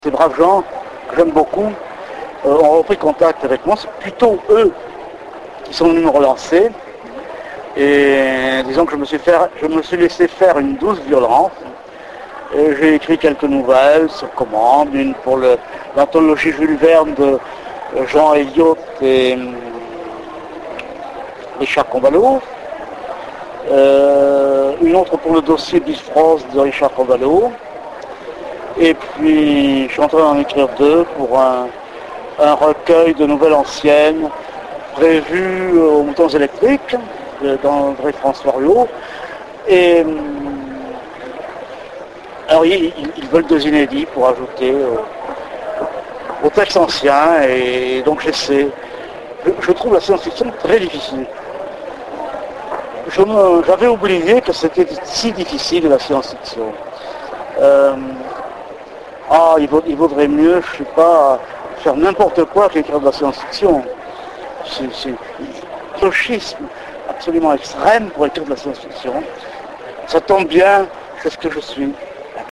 Interview Michel Jeury - Mai 2007
La réponse de Michel Jeury